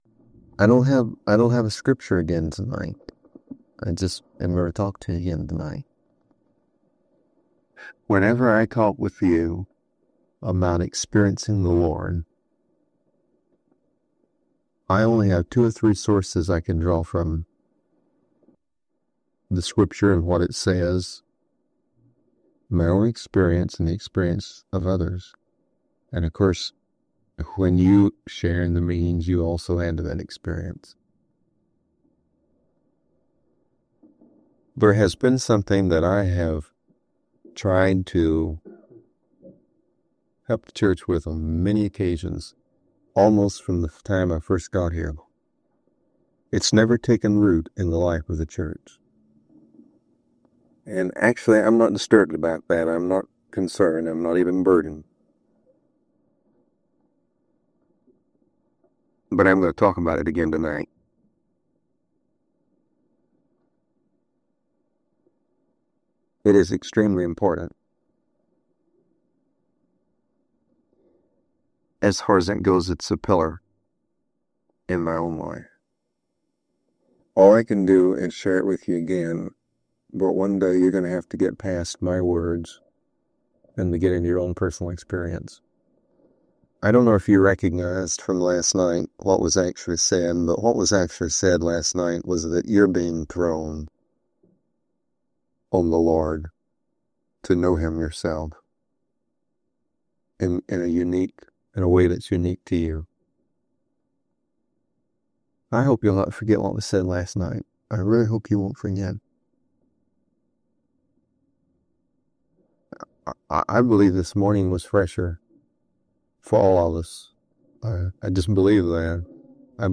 A practical teaching on using Scripture as prayer—moving beyond devotionals into real, living encounters with God.